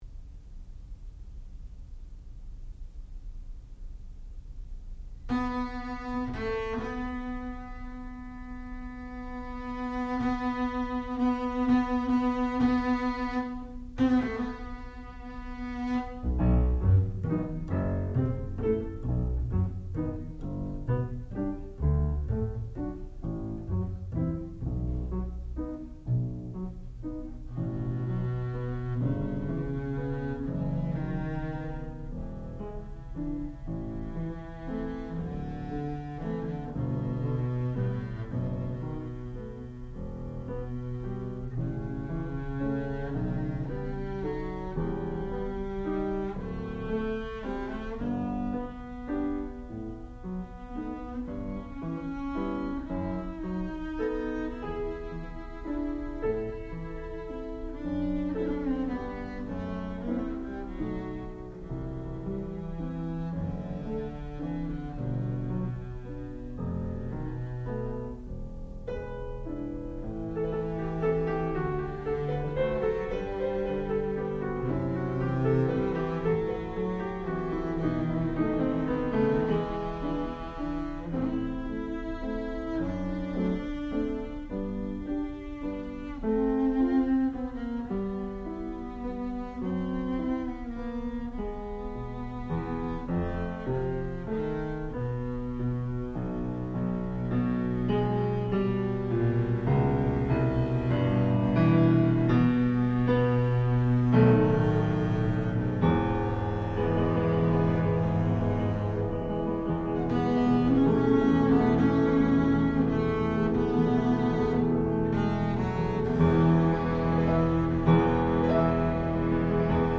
sound recording-musical
classical music
double bass
piano
Advanced Recital